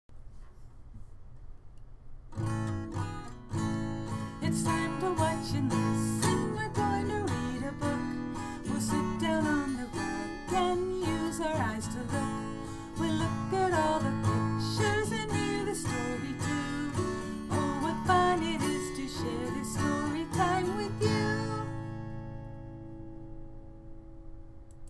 DOMAIN(S) Social and Emotional Development (SED) INDICATOR(S) SED3.4d Tune: "Sing a Song of Sixpence" It's time to watch and listen, We're going to read a book.